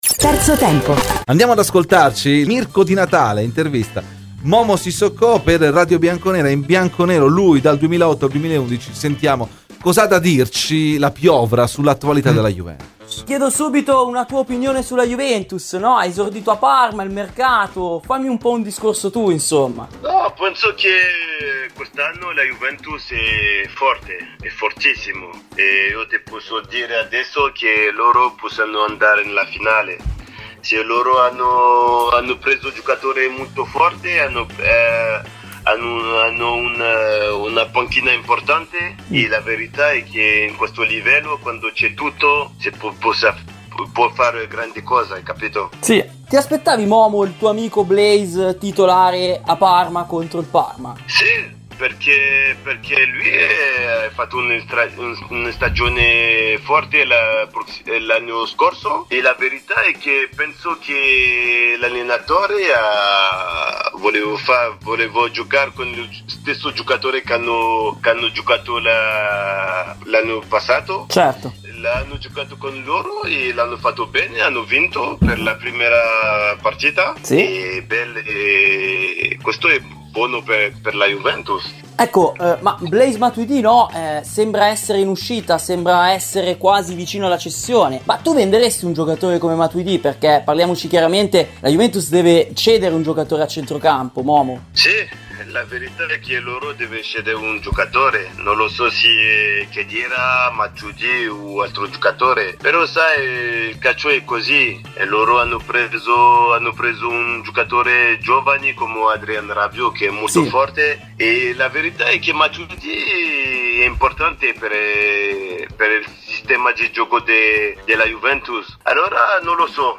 Mohamed Sissoko, ex giocatore della Juventus, parlato ai microfoni di Radio Bianconera: “Penso che quest’anno la Juventus è fortissima, secondo me possono andare in finale di Champions. Hanno una panchina importante e a questo livello quando si ha tutto si possono fare grandi cose”.